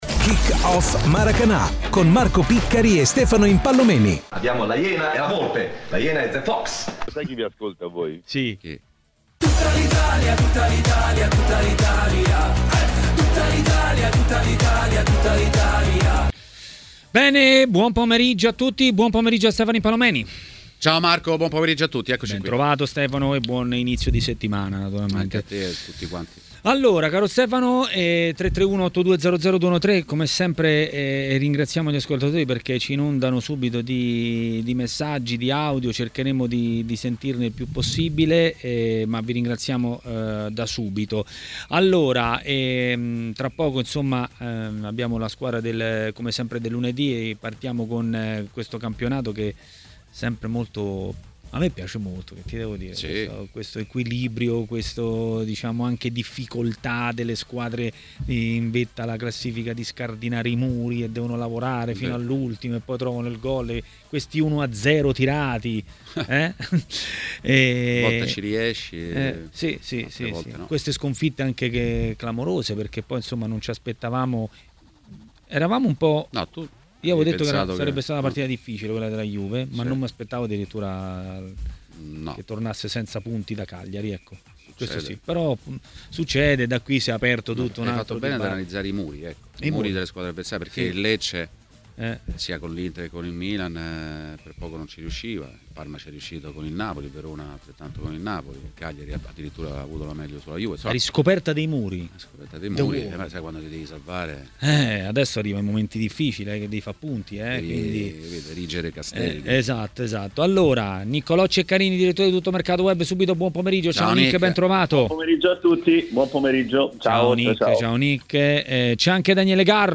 è intervenuto a Maracanà, nel pomeriggio di TMW Radio.